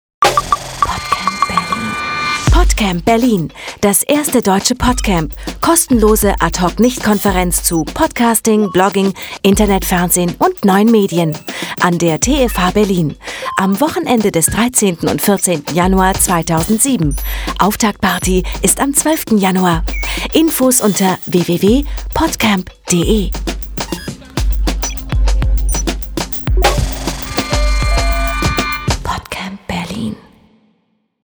Die Sound-Trailer fuer das PodCamp sind fertig.
Vielen Dank an unseren Sponsor dynamicaudio für das grossartige Sounddesign in den sehr gelungenen Spots.